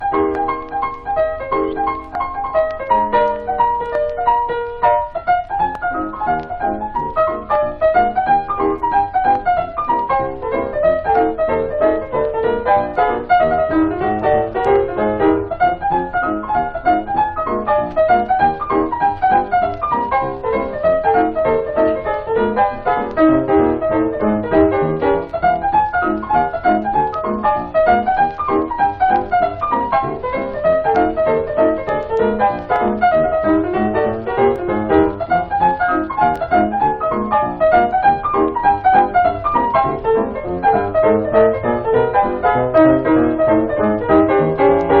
Jazz, Blues, Ragtime　USA　12inchレコード　33rpm　Mono